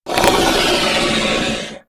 revenant_dies.ogg